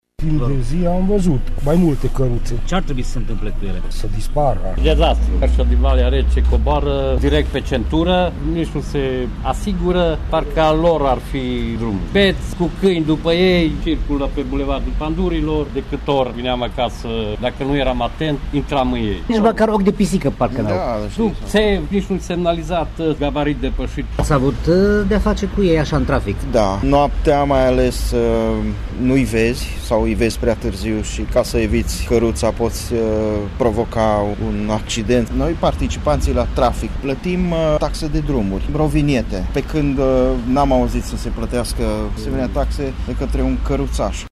Șoferii târgumureșeni doresc interzicerea totală a accesului căruțelor pe șoselele municipiului, pentru că proprietarii acestora nu respectă regulile de circulație, nu plătesc taxa de drum și sunt un pericol pentru ceilalți participanți la trafic: